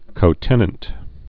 (kō-tĕnənt)